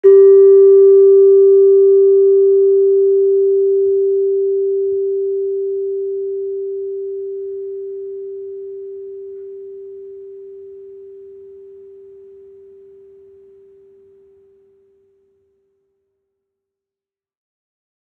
Gamelan
Gender-2-A2-f.wav